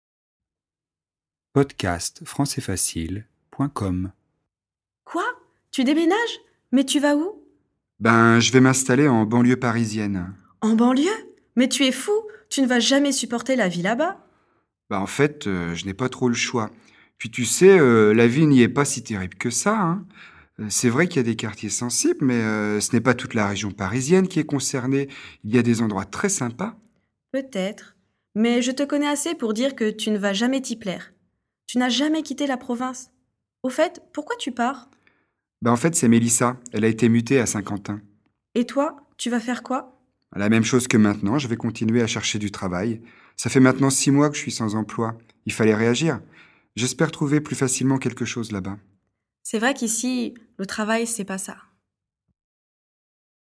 🔷 DIALOGUE